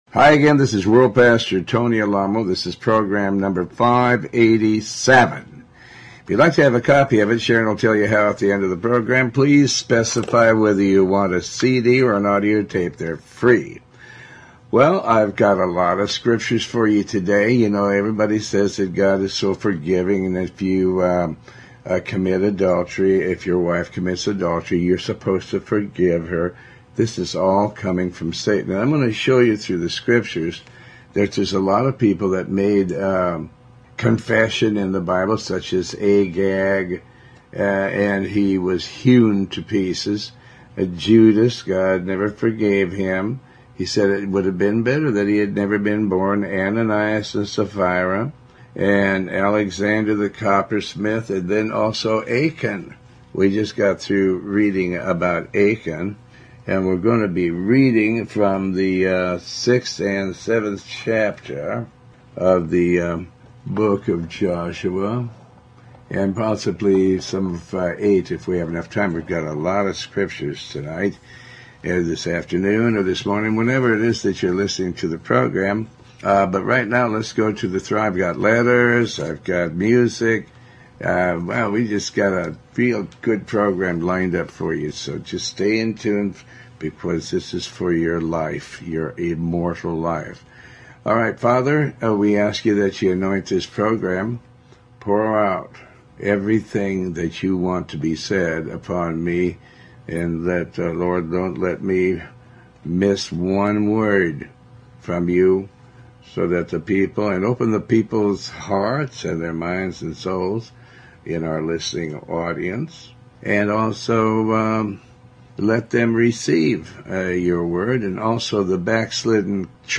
Talk Show Episode, Audio Podcast, Tony Alamo and The Lord made them stay out in the wilderness 40 years.